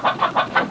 Hen (short) Sound Effect
hen-short.mp3